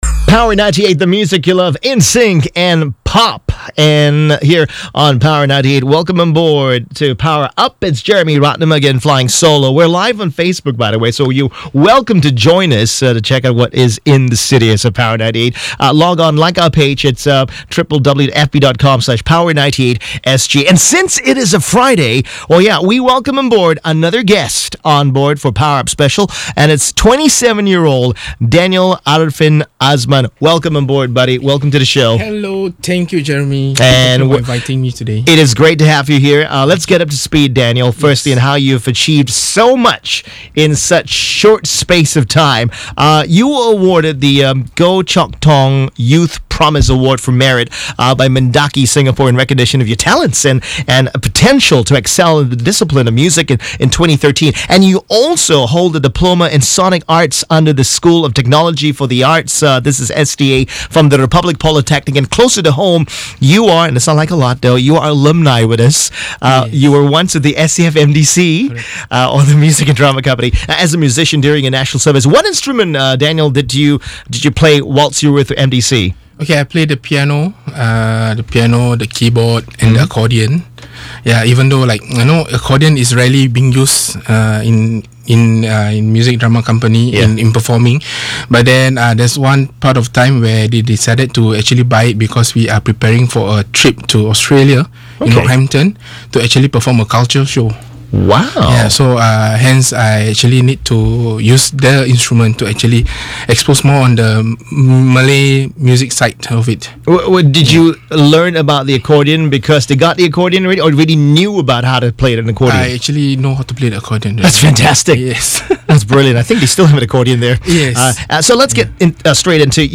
LIVE Radio Interview on Power 98FM